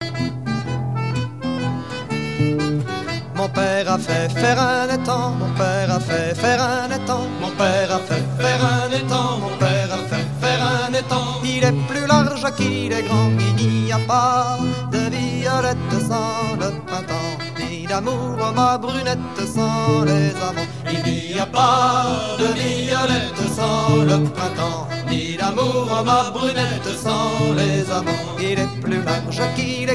gestuel : à marcher
circonstance : fiançaille, noce
Pièce musicale éditée